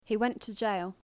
/j/ yacht versus / dʒ / jot